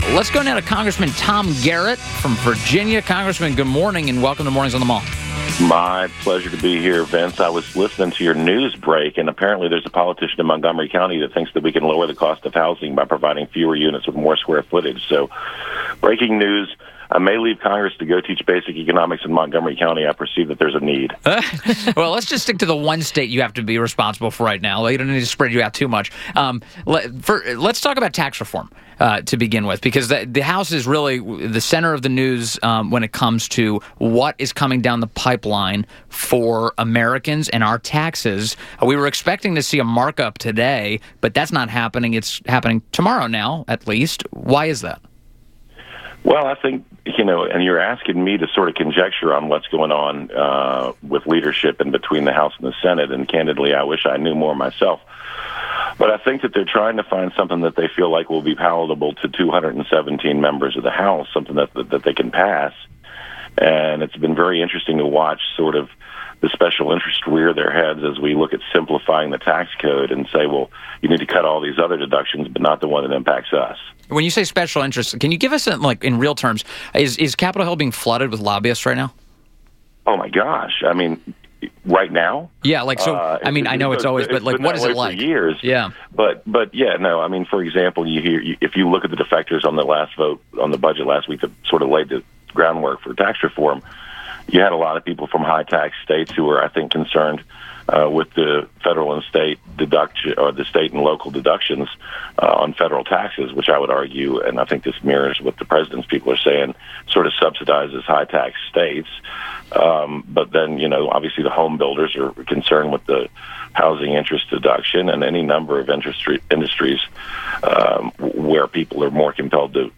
INTERVIEW -- REP. TOM GARRETT (Virginia - 5th District)